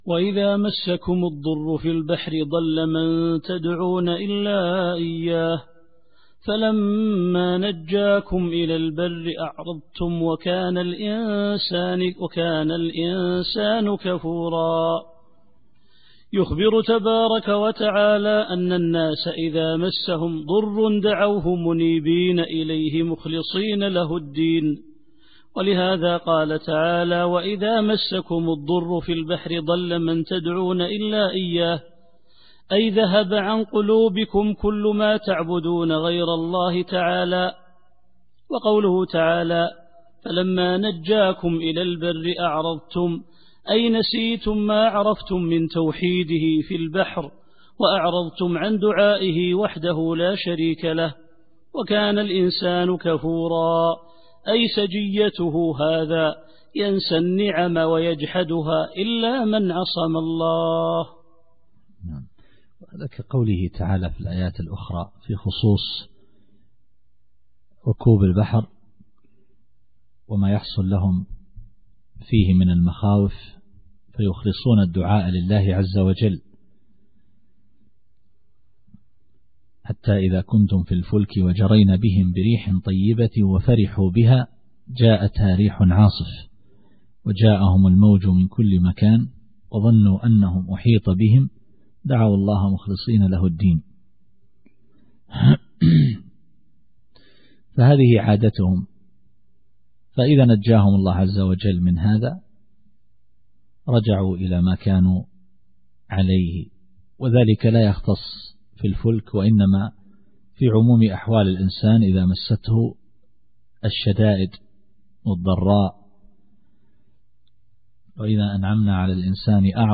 التفسير الصوتي [الإسراء / 67]